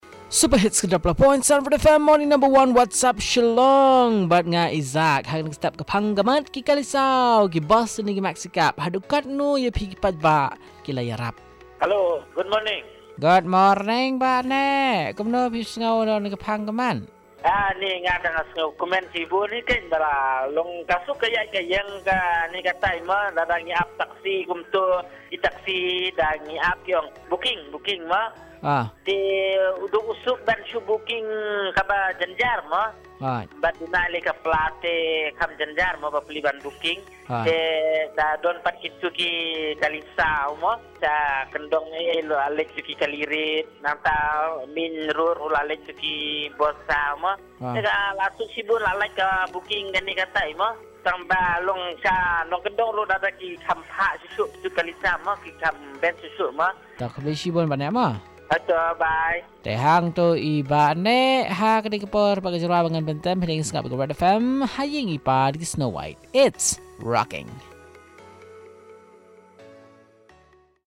Caller 3 on Red Bus and Maxi Cabs